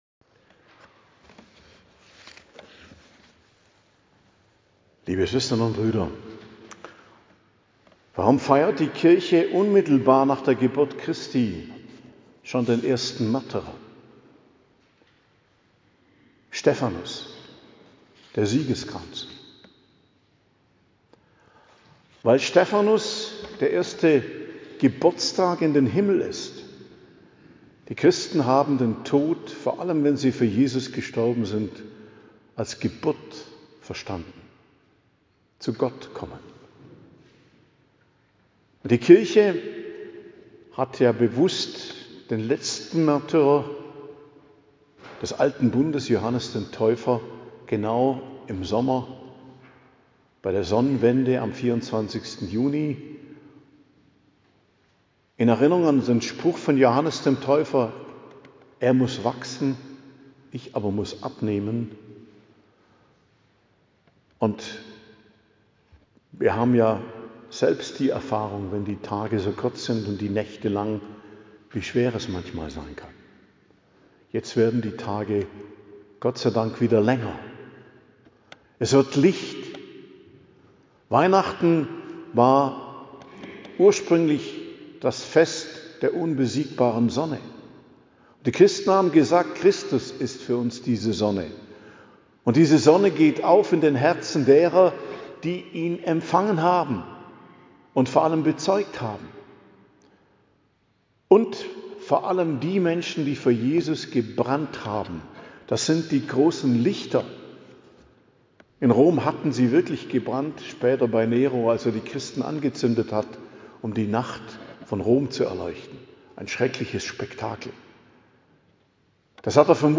Predigt am Fest des Hl Stephanus, erster Märtyrer der Kirche, 26.12.2025